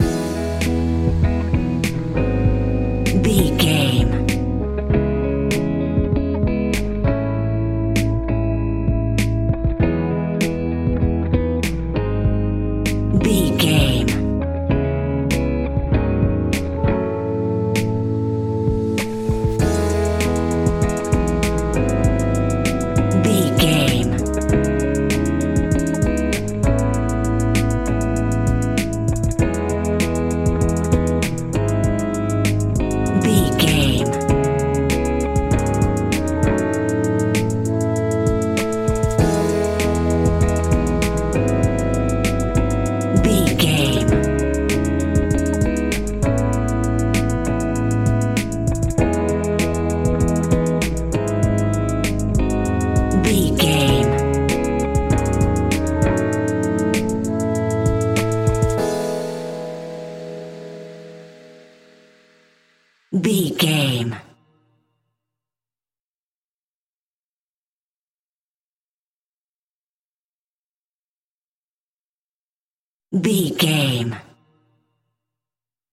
Ionian/Major
hip hop instrumentals
chilled
laid back
groove
hip hop drums
hip hop synths
piano
hip hop pads